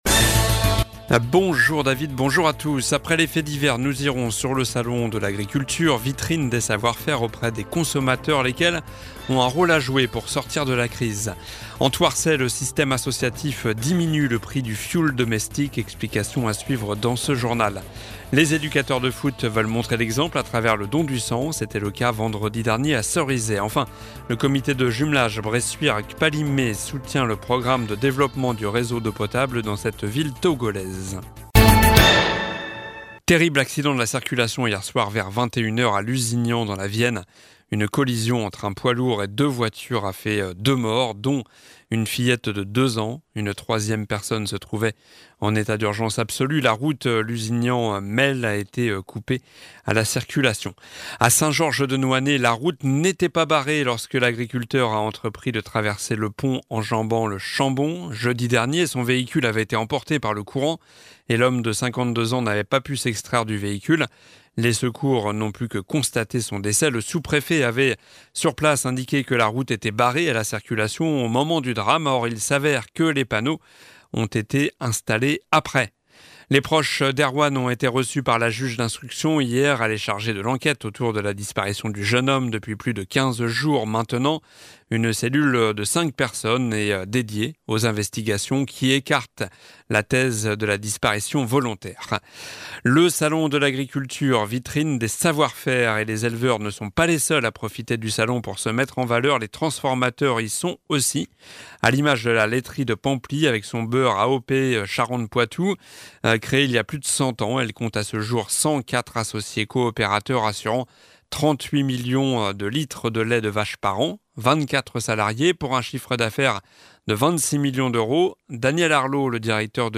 Journal du mardi 27 février (midi)